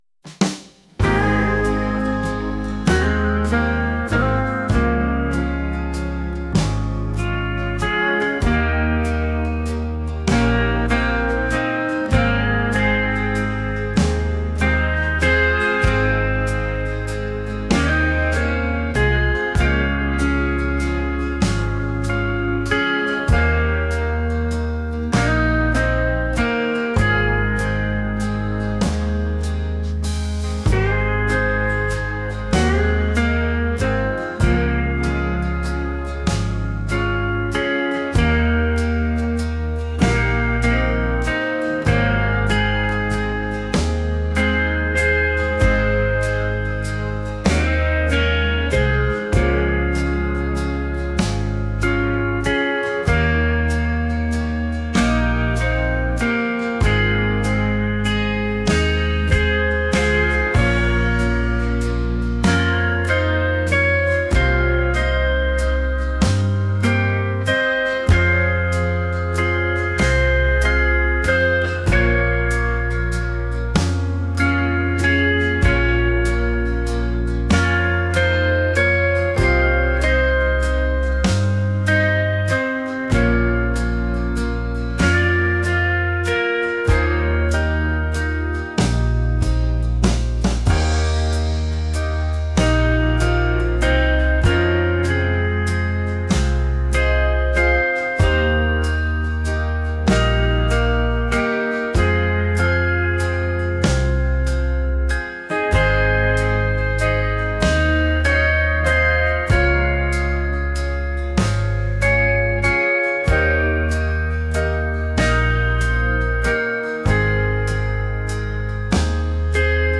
country | soulful